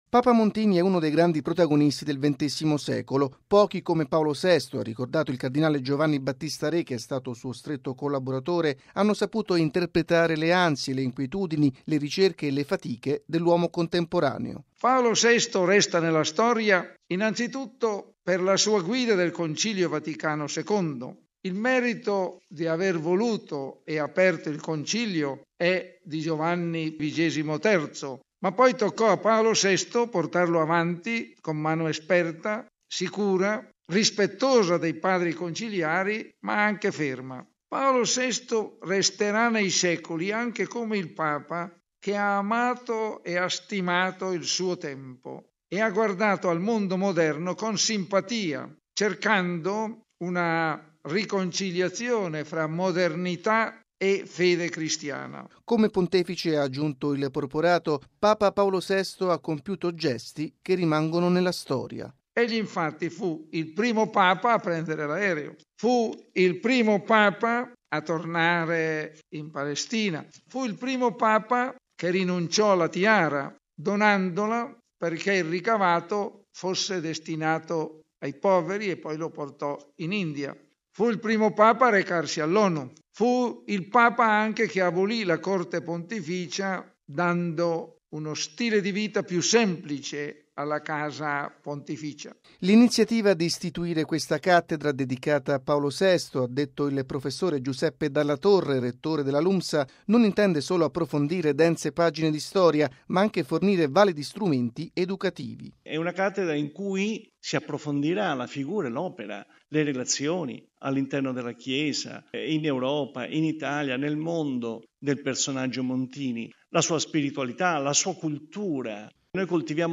E’ la finalità dell’istituzione di una nuova Cattedra, intitolata alla figura di Giovanni Battista Montini – Papa Paolo VI, nell’ambito di un corso di Storia contemporanea della Libera Università Maria Santissima Assunta (Lumsa). L’iniziativa è stata presentata stamani nella Sala Stampa della Santa Sede. Il servizio